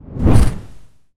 whoosh_magic_spell_03.wav